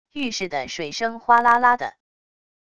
浴室的水声哗啦啦的wav音频